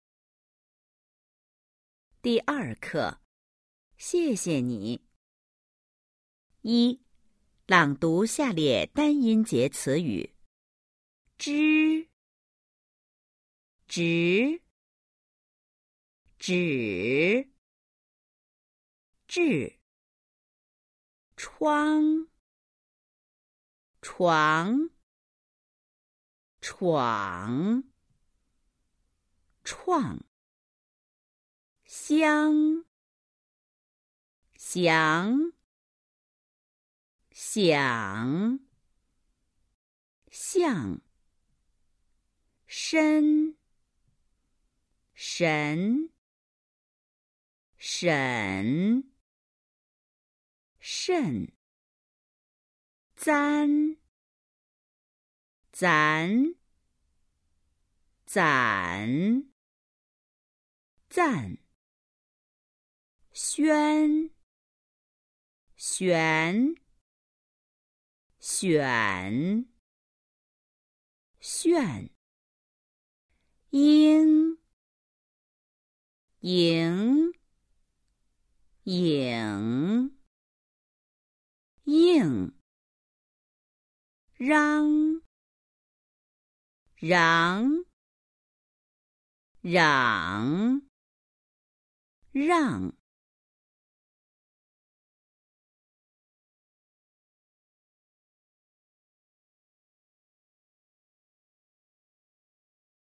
一、朗读下列单音节词语　💿 02-1
Đọc to các từ có một âm tiết dưới đây.